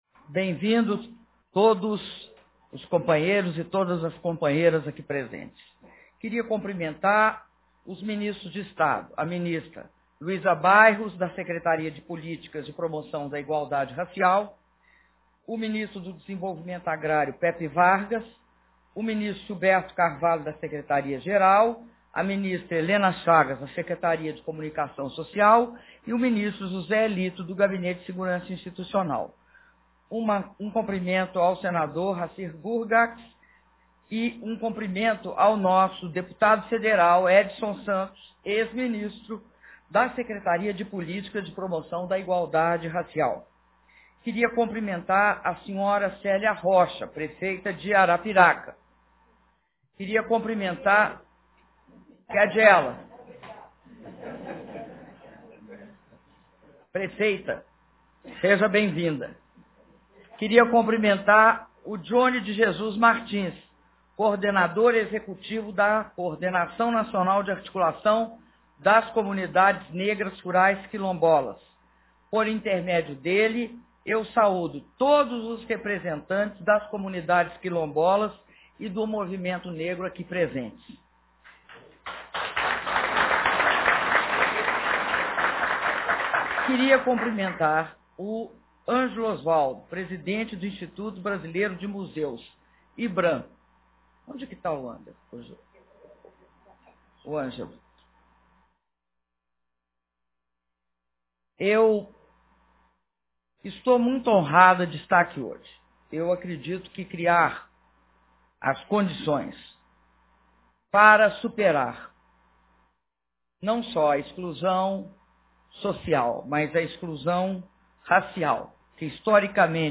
Áudio do discurso da Presidenta da República, Dilma Rousseff, durante a assinatura de decretos de desapropriação e imissão de posse para as comunidades quilombolas - Brasília/DF